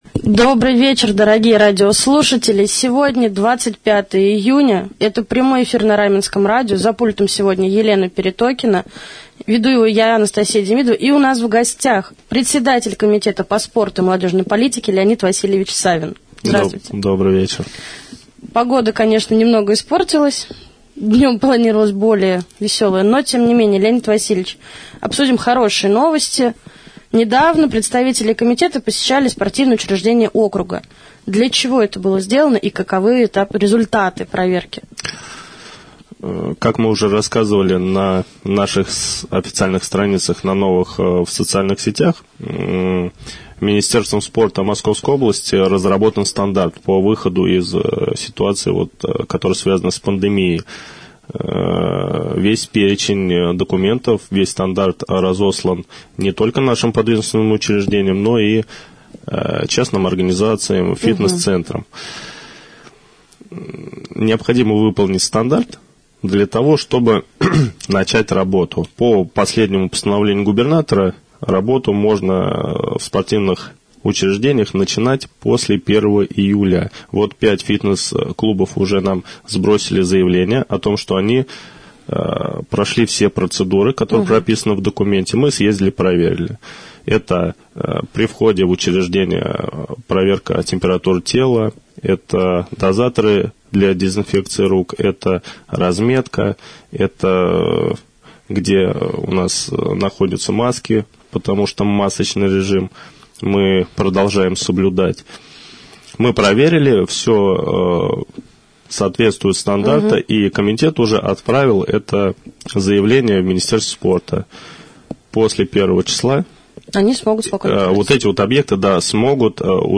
Председатель Комитета по спорту и молодежной политике Леонид Васильевич Савин стал гостем прямого эфира на Раменском радио 25 июня.
prjamoj-jefir-1.mp3